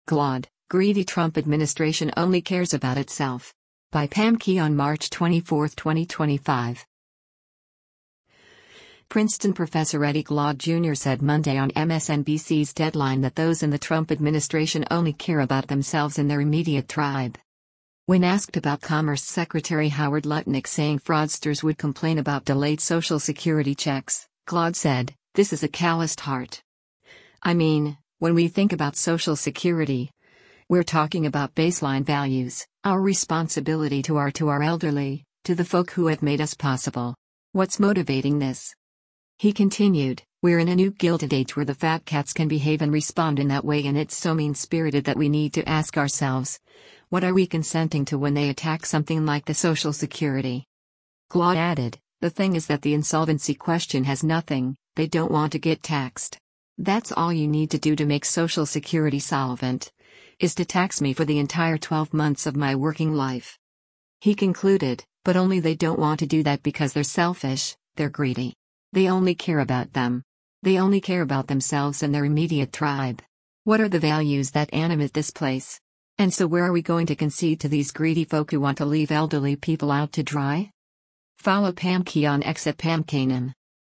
Princeton professor Eddie Glaude Jr. said Monday on MSNBC’s “Deadline” that those in the Trump administration “only care about themselves and their immediate tribe.”